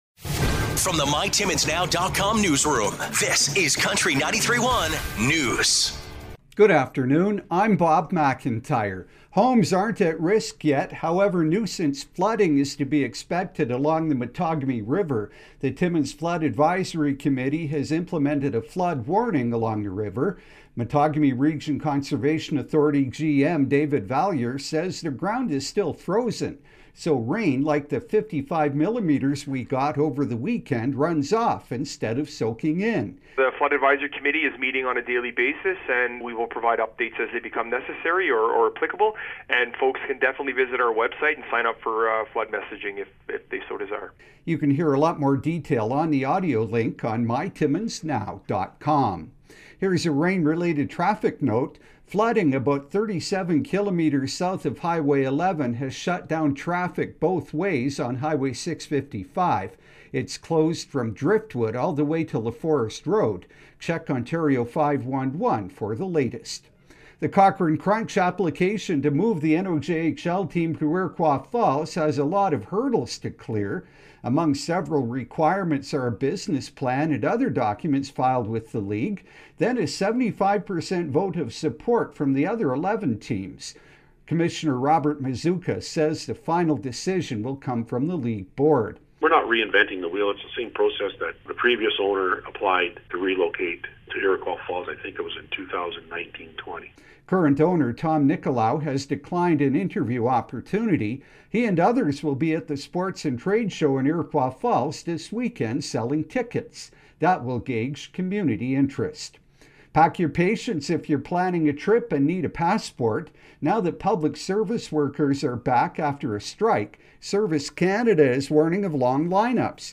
5:00pm Country 93.1 News – Tues., May 2, 2023